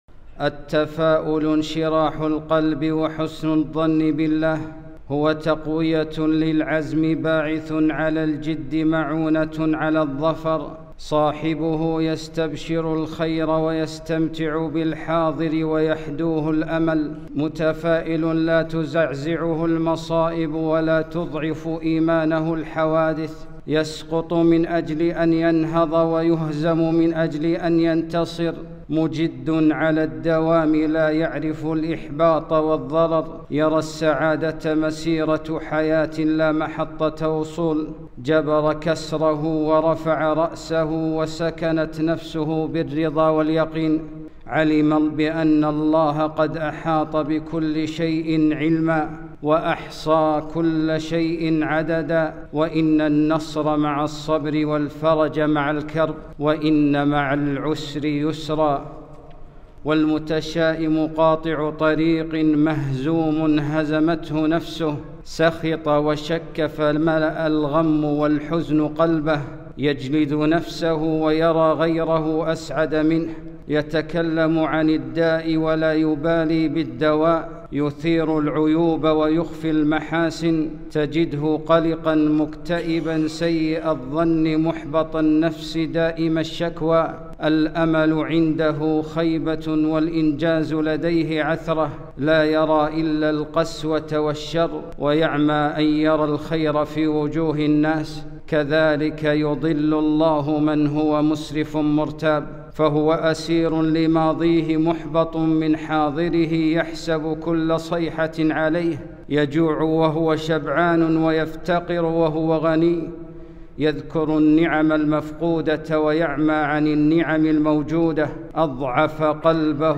خطبة - احذروا قاطع الطريق